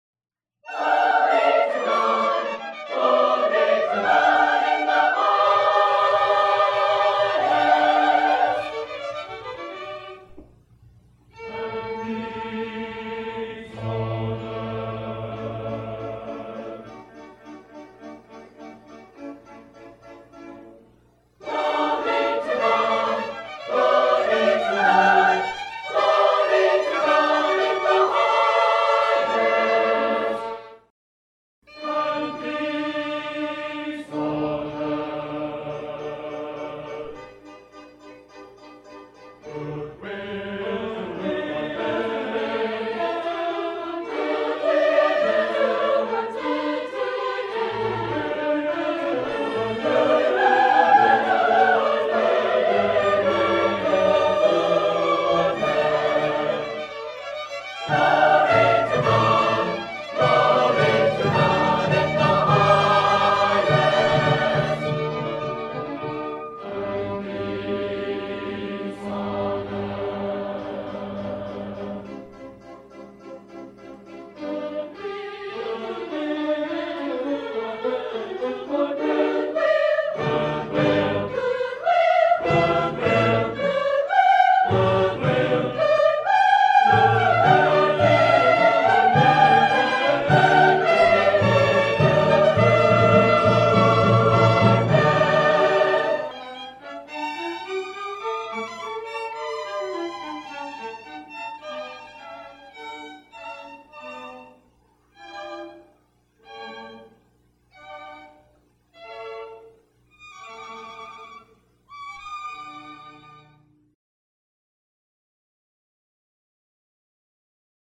Bethany Nazarene College Choral Society & Orchestra present Handel's Messiah. Performed and recorded in Herrick Auditorium on the campus of Bethany Nazarene College on 07 December 1981 at 8:00PM.